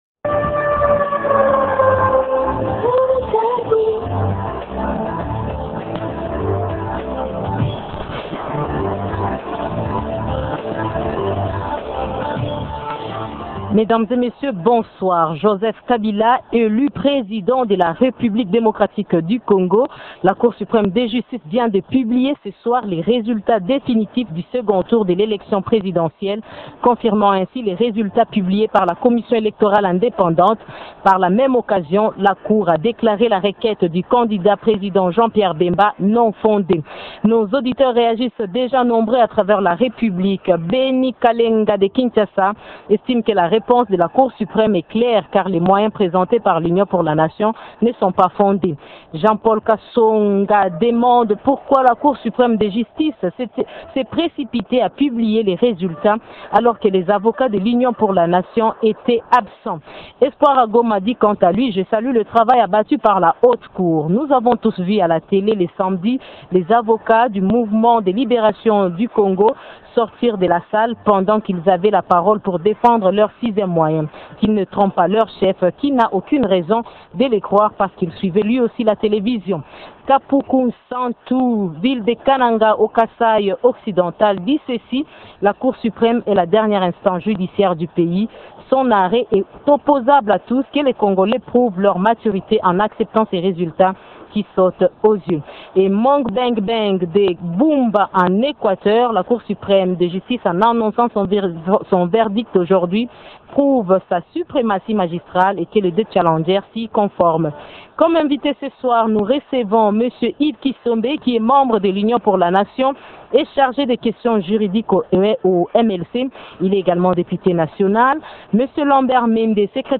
Deux questions au centre de notre débat de ce soir :